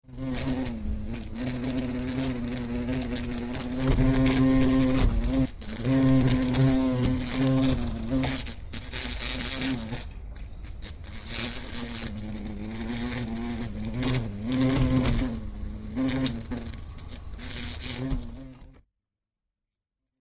Звуки шершня
Шершень громко жужжит